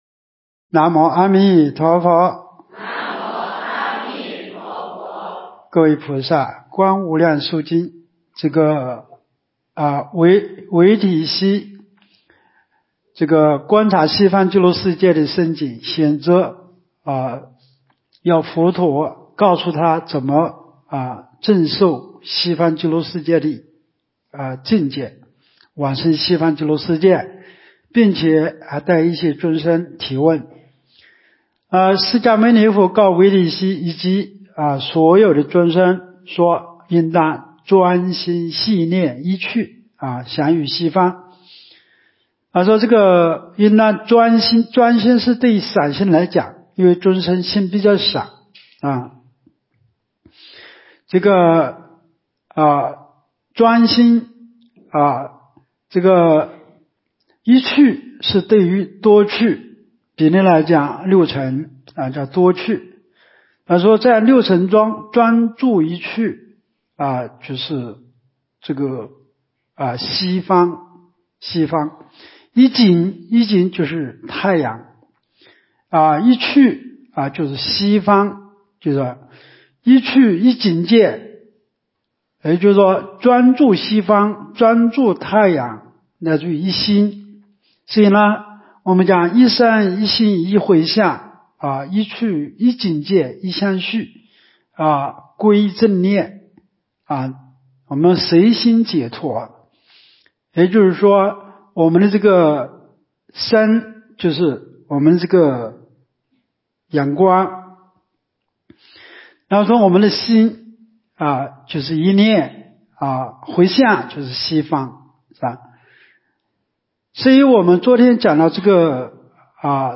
无量寿寺冬季极乐法会精进佛七开示（17）（观无量寿佛经）...